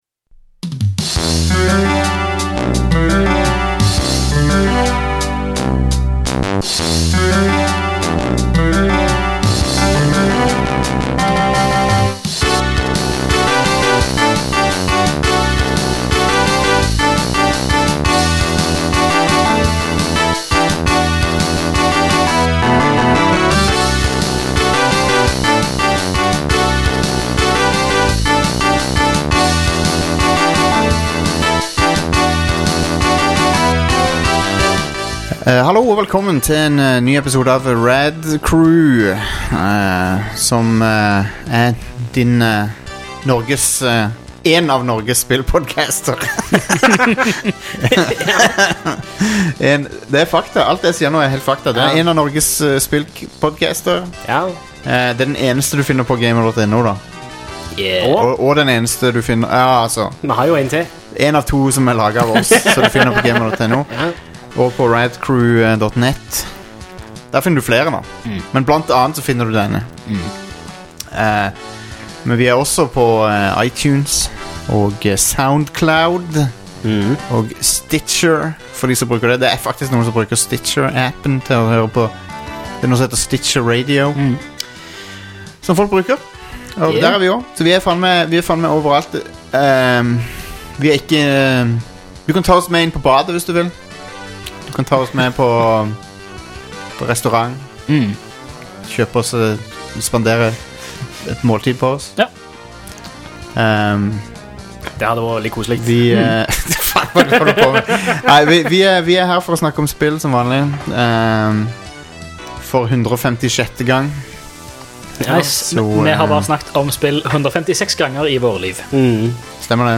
Podkaster om spill, popkultur, film og TV siden 2011